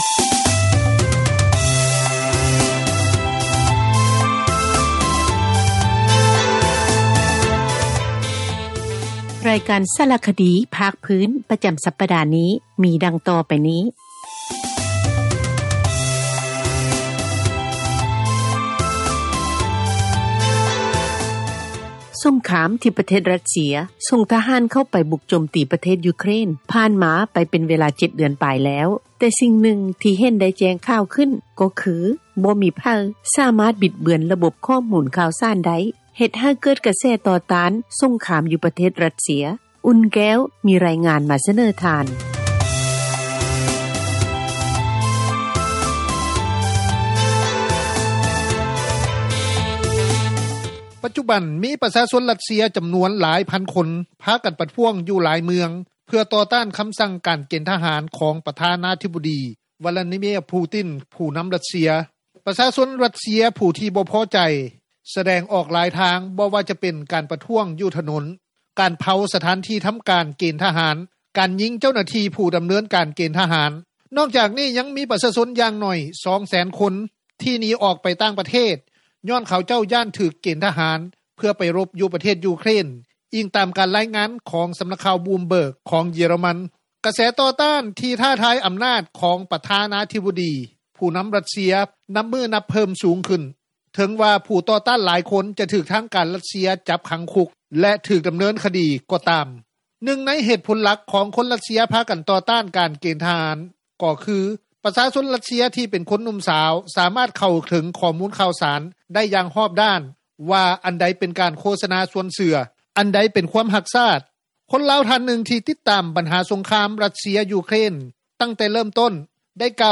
ຄົນລາວ ທ່ານນຶ່ງທີ່ຕິດຕາມບັນຫາສົງຄາມ ຣັສເຊັຽ-ຢູເຄຣນ ແຕ່ເຣີ່ມຕົ້ນ ໄດ້ກ່າວຕໍ່ວິທຍຸ ເອເຊັຽເສຣີ ວ່າ:
ເຈົ້າໜ້າທີ່ ອົງການຈັດຕັ້ງ ພາກປະຊາສັງຄົມລາວ ທ່ານນຶ່ງ ໄດ້ກ່າວຕໍ່ວິທຍຸ ເອເຊັຽເສຣີ ວ່າ: